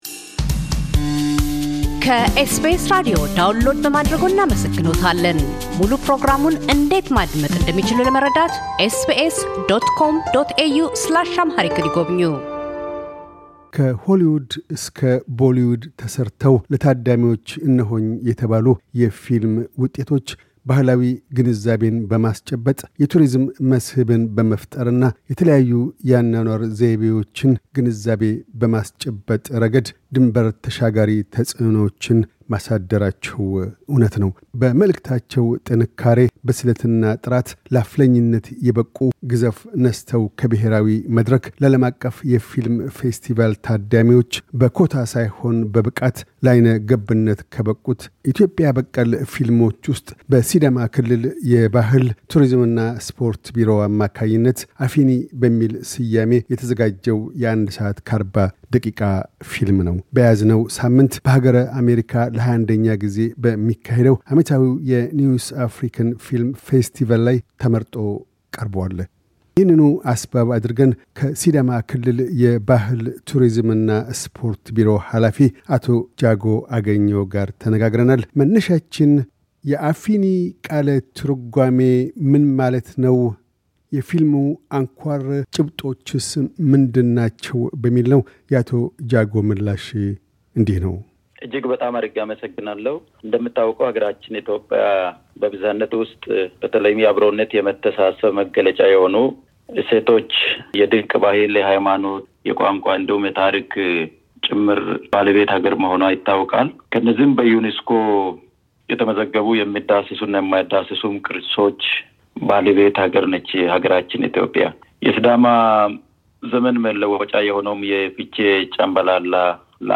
አቶ ጃጎ አገኘሁ፤ የሲዳማ ክልል የባሕል፣ ቱሪዝምና ስፖርት ቢሮ ኃላፊ፤ ሰሞኑን በቢሯቸው ተደግፎ በ21ኛው New African Film Festival ላይ ቀርቦ ስላለው "አፊኒ" ፊልም ፍቺ፣ ጭብጦችና ስኬቶች ይናገራሉ።